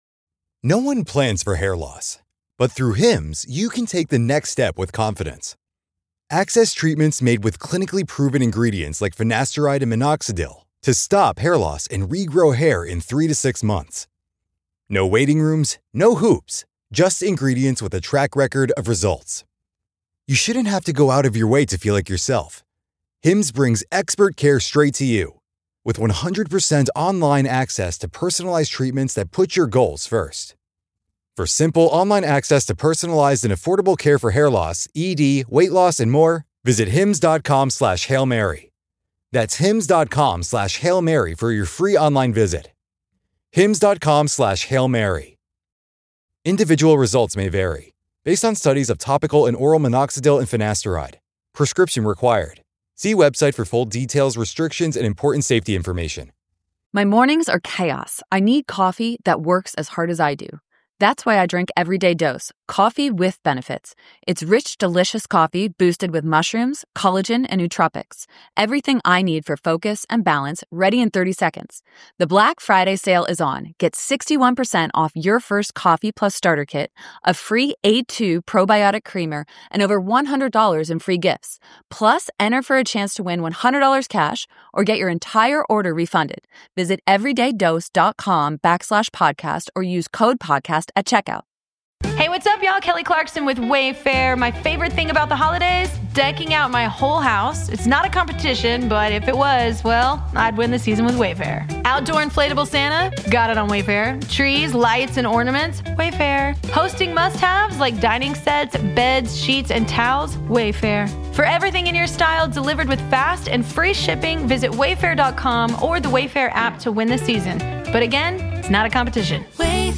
Crazy fun banter revolves around what the ‘squares’ and ‘sharps’ are looking at in Vegas.